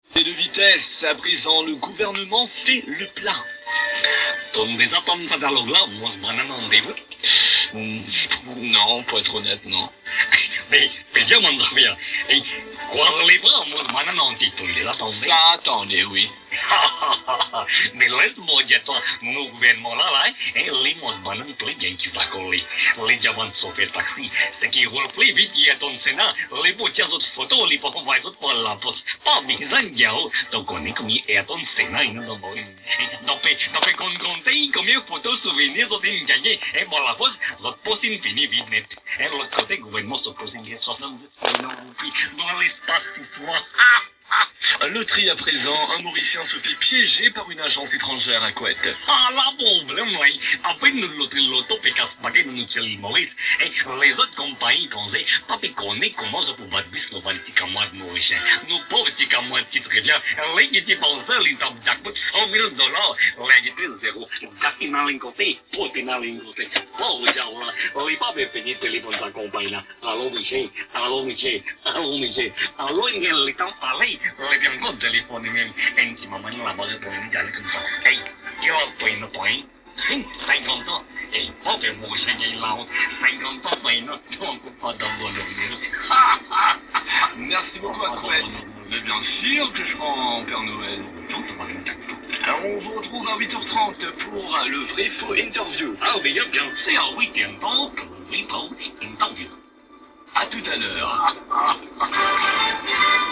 Je vous invite à écouter cet extrait d’une émission diffusée sur les airs de cette radio tous les samedis et peut être les dimanches…